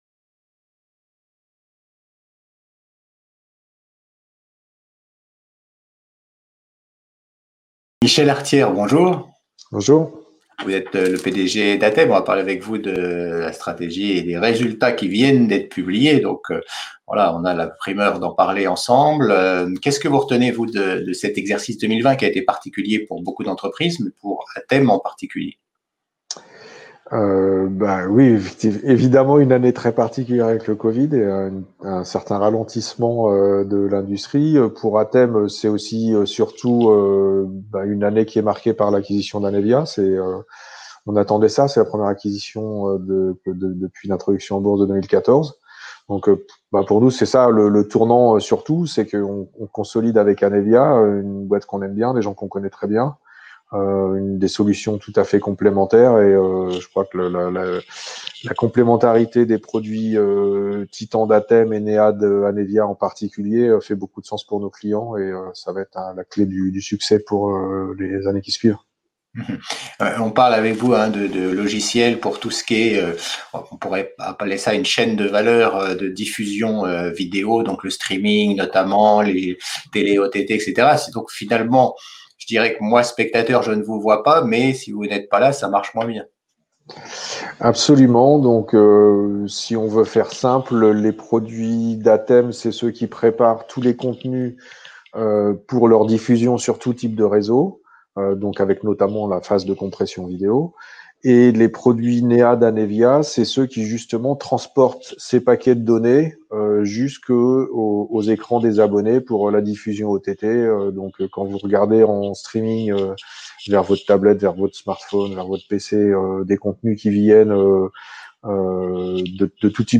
Retour sur cette actualité, la stratégie de l’entreprise qui vise davantage de revenus récurrents et s’en explique dans cette interview.